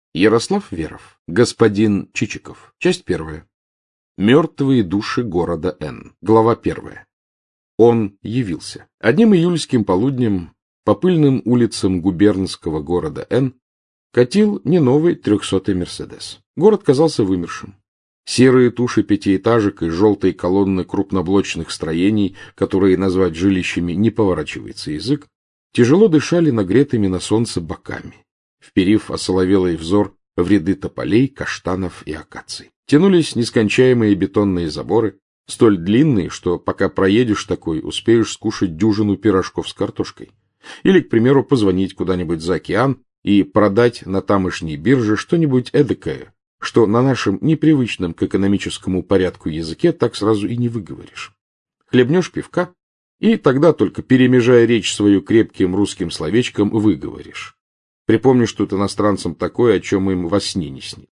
Мертвые души города N Автор Ярослав Веров Читает аудиокнигу Александр Клюквин.